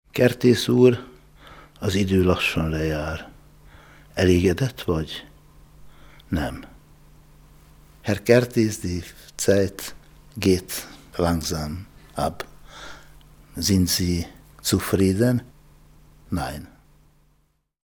Er hat über 90 Schriftsteller gebeten, sich selbst eine Frage zu stellen und sie dann zu beantworten.
Deutschlandradio Kultur sendet die Fragen und Antworten als Mini-Selbstgespräche in der Zeit vom 8.10. bis 14.10.07 jeweils in "Fazit am Abend" und in "Fazit" - parallel zur Frankfurter Buchmesse.